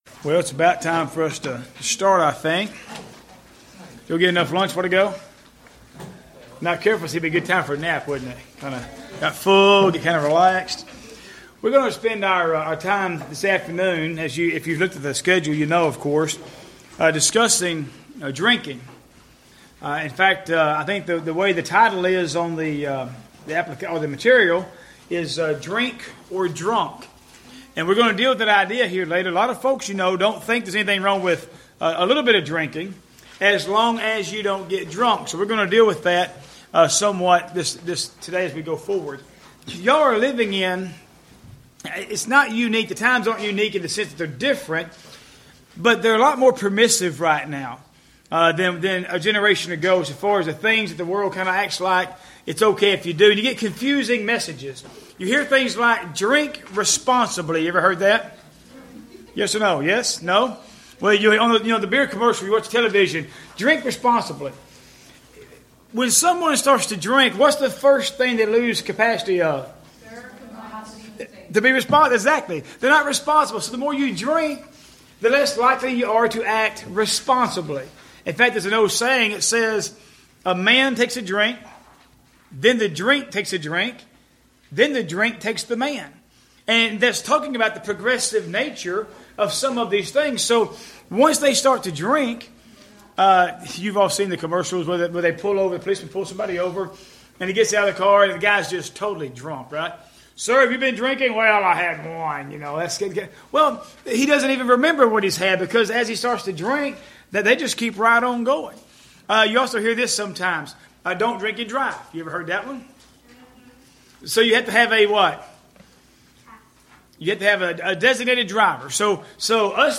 Youth Sessions , Alcohol , Social Drinking , Drunkeness